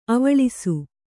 ♪ avaḷisu